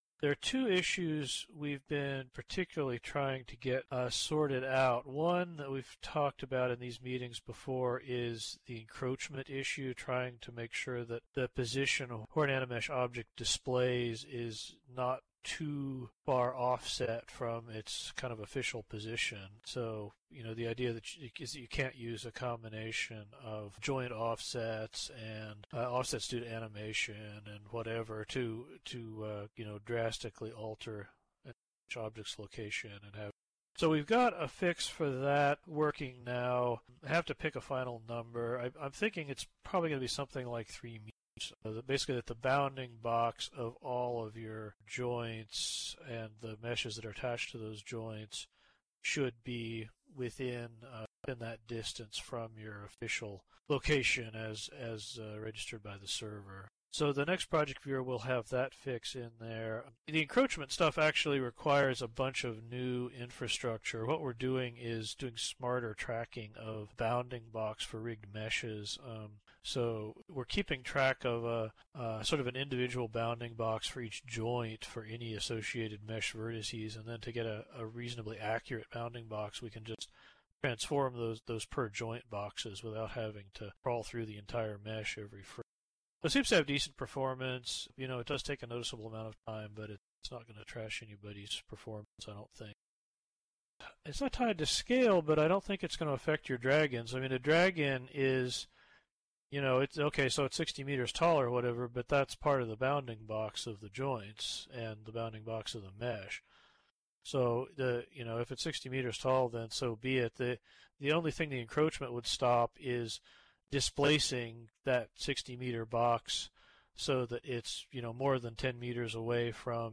The following notes are taken from the Content Creation User Group (CCUG) meeting, held on  Thursday, June 14th, 2018 at 13:00 SLT.